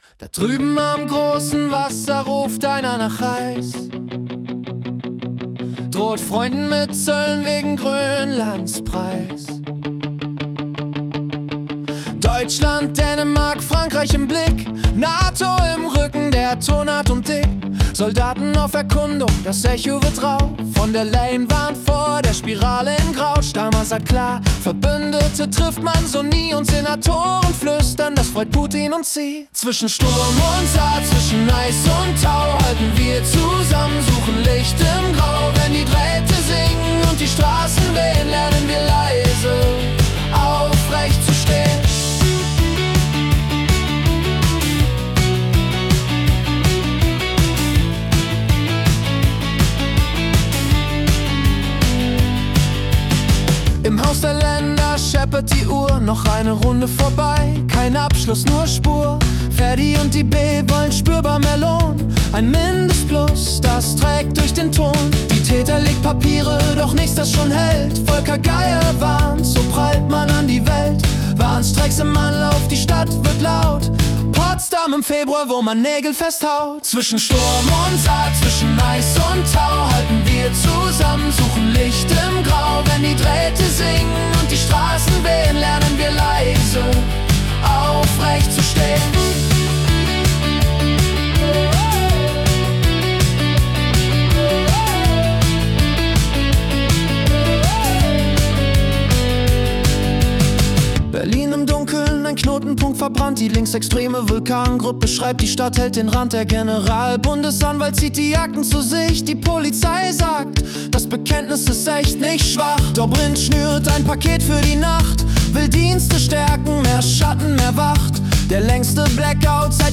Die Nachrichten vom 18. Januar 2026 als Singer-Songwriter-Song interpretiert.